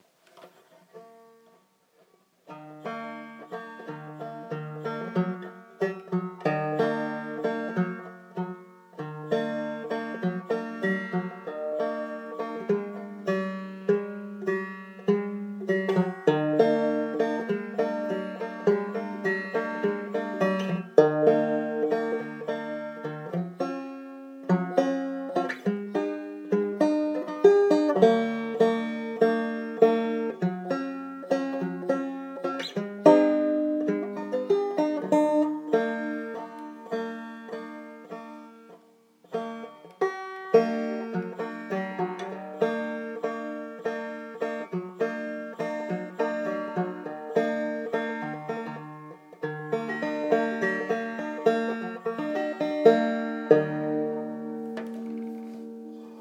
bit of banjo